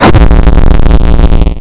gameover.wav